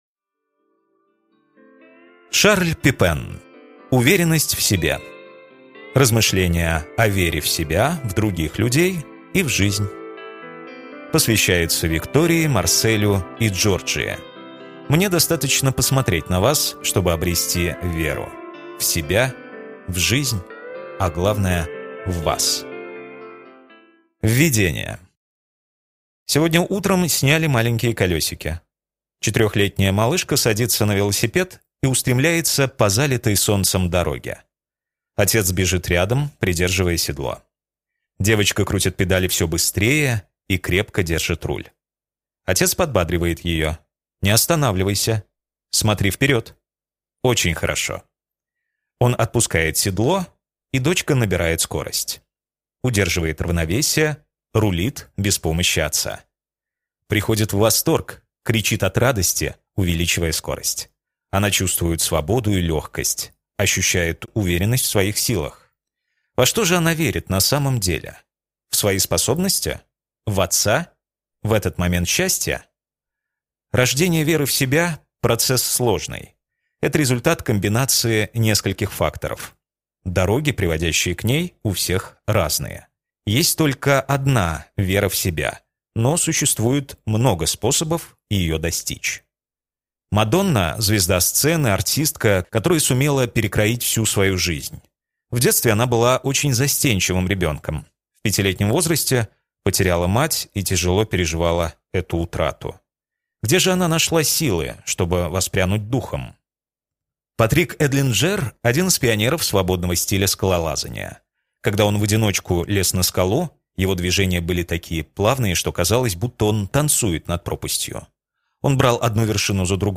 Аудиокнига Уверенность в себе | Библиотека аудиокниг